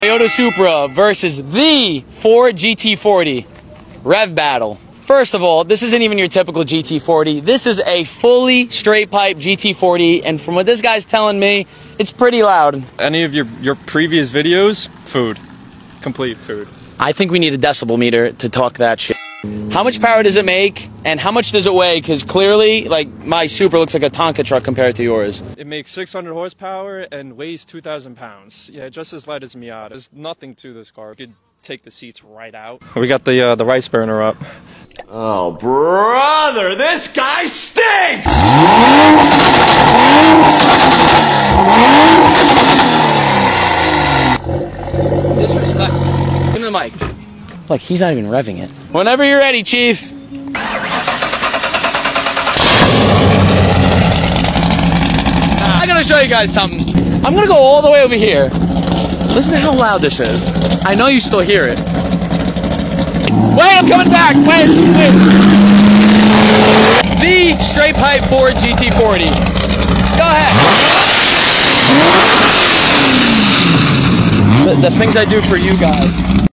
STRAIGHT PIPE Ford GT 40 VS. sound effects free download